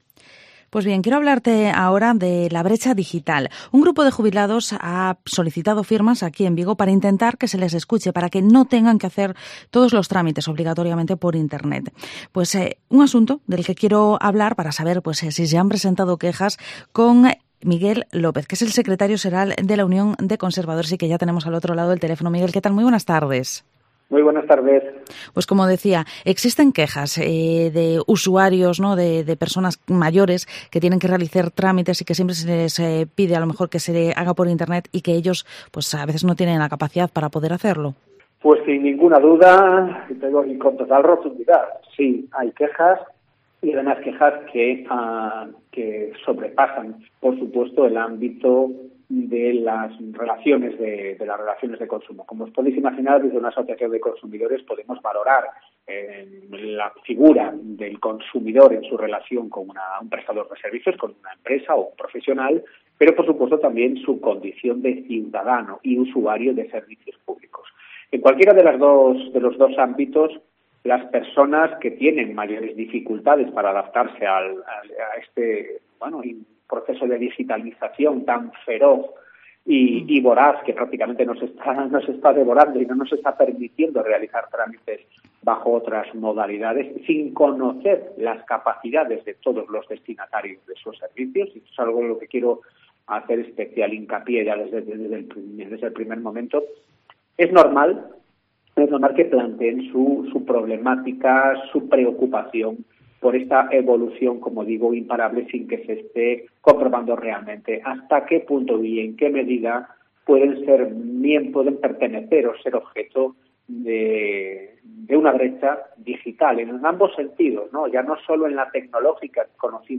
Vigo Entrevista Brecha Digital.